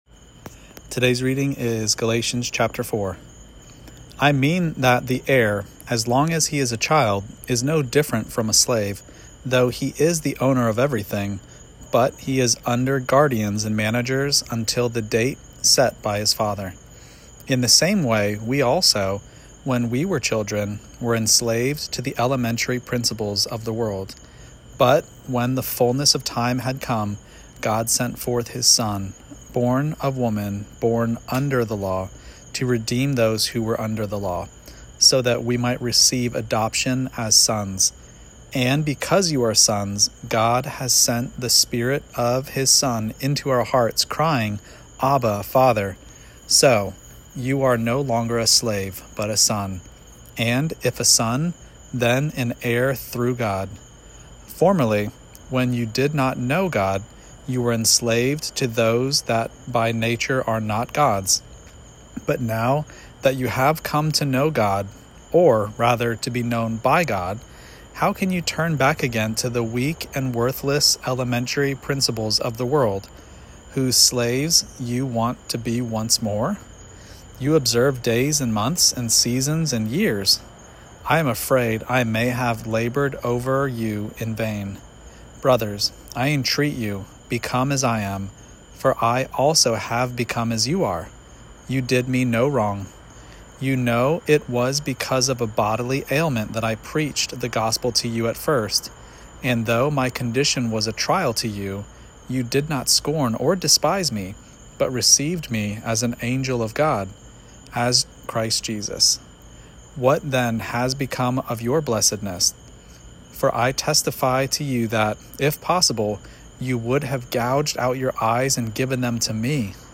Daily Bible Reading (ESV) September 25: Galatians 4 Play Episode Pause Episode Mute/Unmute Episode Rewind 10 Seconds 1x Fast Forward 30 seconds 00:00 / 4:14 Subscribe Share Apple Podcasts Spotify RSS Feed Share Link Embed